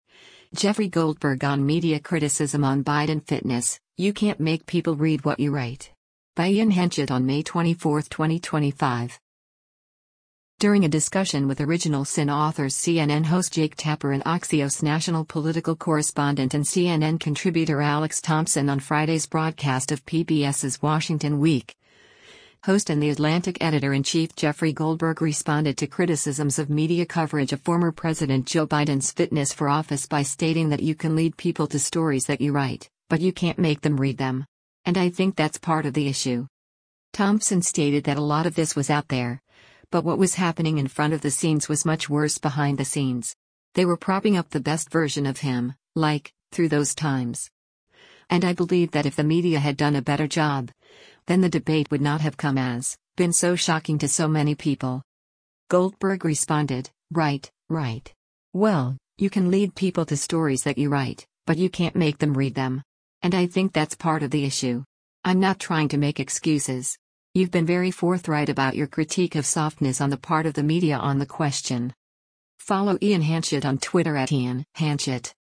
During a discussion with “Original Sin” authors CNN host Jake Tapper and Axios National Political Correspondent and CNN Contributor Alex Thompson on Friday’s broadcast of PBS’s “Washington Week,” host and The Atlantic Editor-in-Chief Jeffrey Goldberg responded to criticisms of media coverage of former President Joe Biden’s fitness for office by stating that “you can lead people to stories that you write, but you can’t make them read them. And I think that’s part of the issue.”